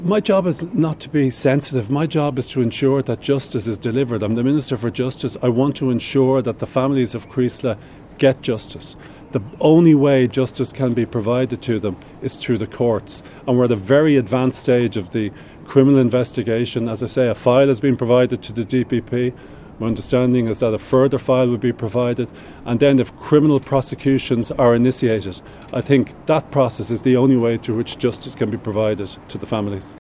Speaking to reporters in Cavan at a cross-border policing event, Minister Jim O’Callaghan acknowledged the families deserve answers, but repeated his belief that inquiries are not the way to achieve that.